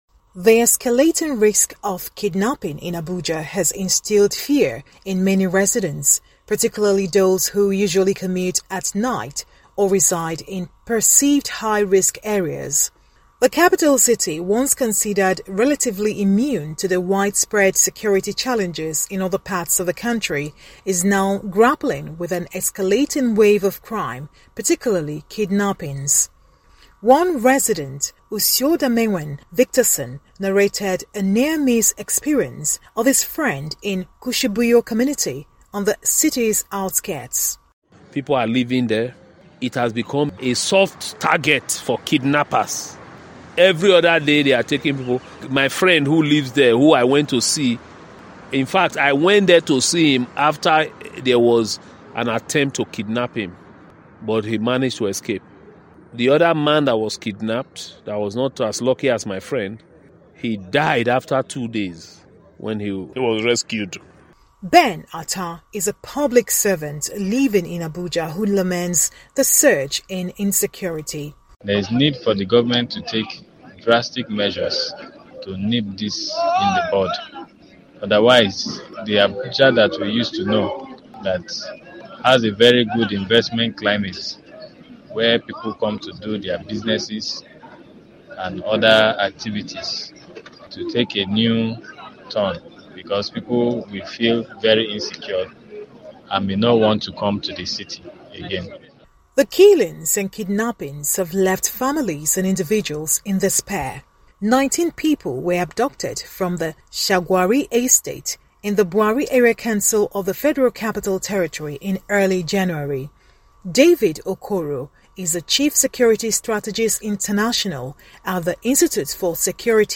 Residents of Abuja, Nigeria, have been on edge because of widespread insecurity around the outskirts of the city, with frequent and indiscriminate killings and kidnappings. Our reporter in Abuja has more.